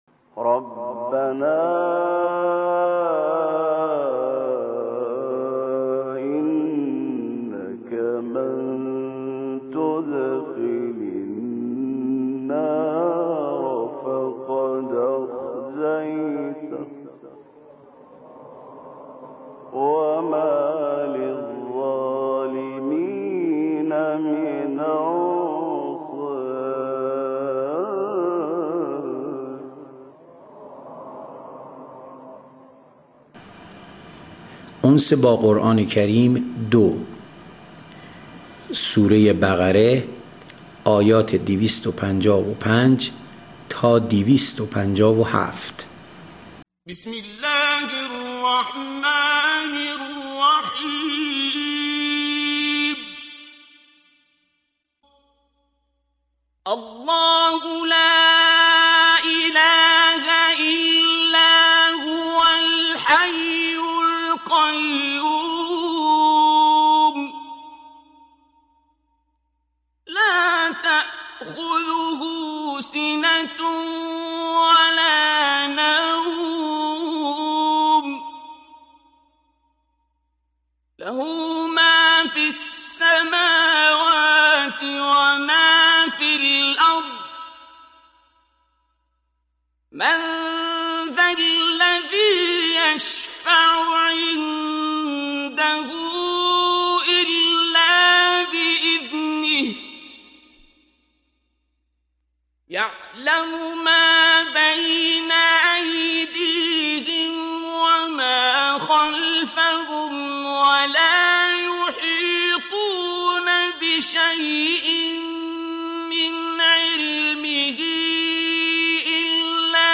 قرائت آیات آیات 255 الی 257 سوره بقره (آیه الکرسی)